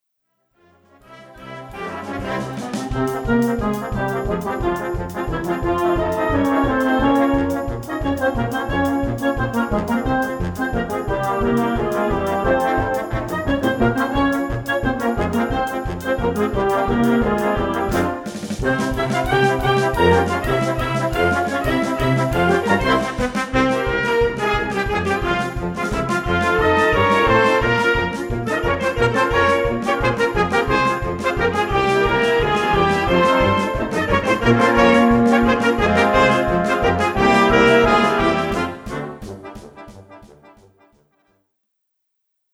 Gattung: Konzertwerk
Besetzung: Blasorchester
Ein mitreißendes Stück zum Feiern!